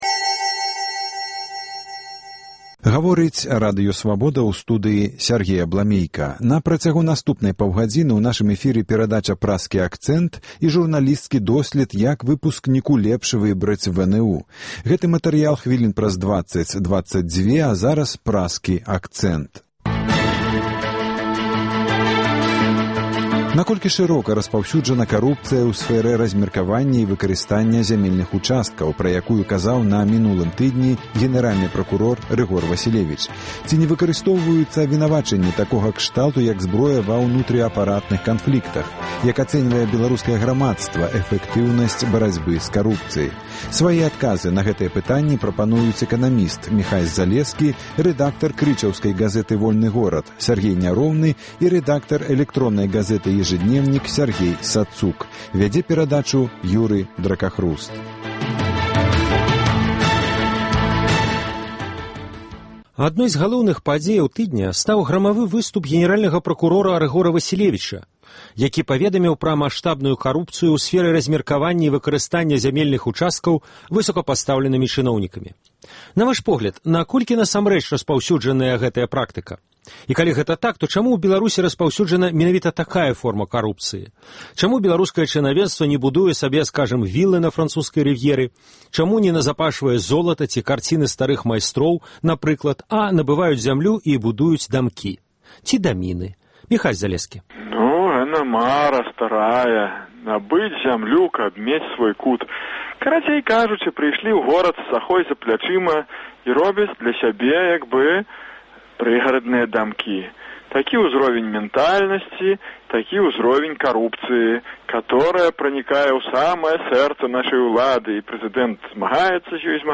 Як ацэньвае беларускае грамадзтва эфэктыўнасьць барацьбы з карупцыяй? У круглым стале